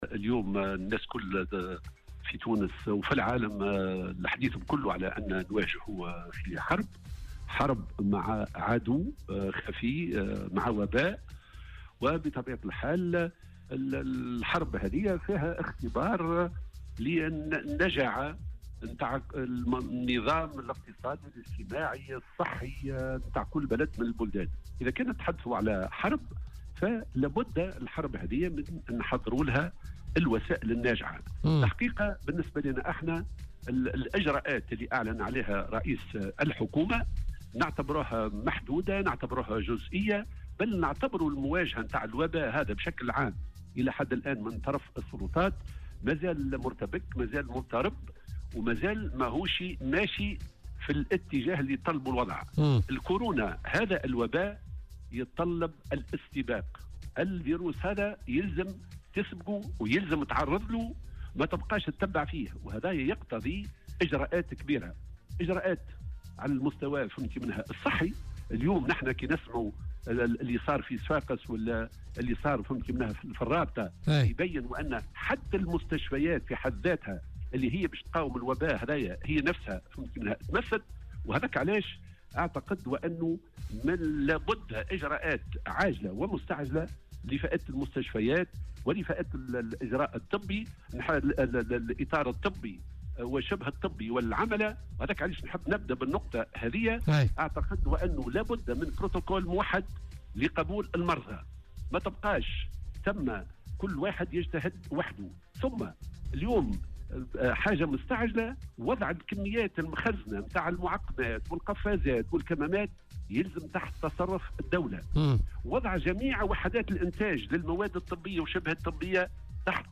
واعتبر في مداخلة له اليوم في برنامج "بوليتيكا" أن مواجهة السلطات لهذا الوباء مازال "مرتبكا" و"مضطربا"، مشيرا إلى أن تونس في حرب وعليها المرور إلى الإجراءات الاستباقية و العاجلة ووضع كل الإمكانيات على ذمة المستشفيات (الكميات المخزنة من قفازات وكمّمات والترفيع في عدد المخابر وتوفير التجهيزات وتسخير المصحات الخاصة لإيواء المرضى..).